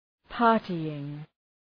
Shkrimi fonetik {‘pɑ:rtııŋ}